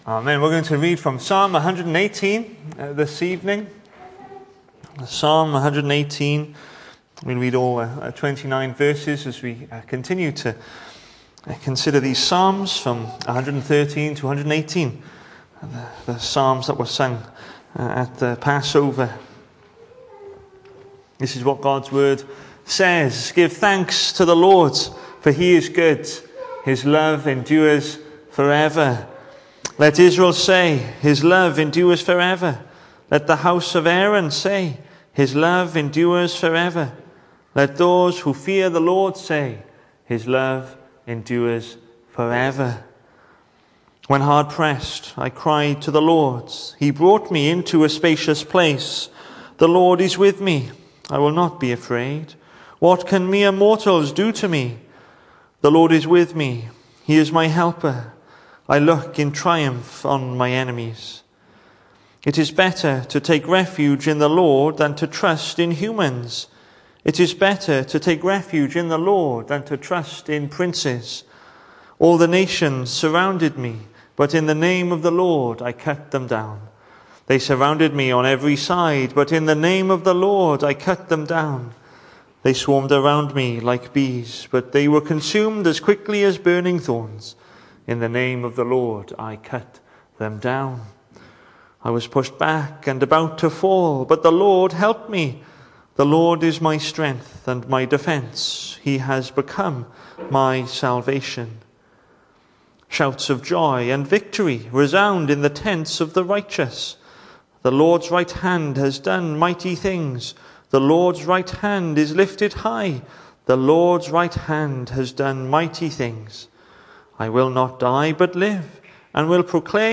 The 13th of April saw us hold our evening service from the building, with a livestream available via Facebook.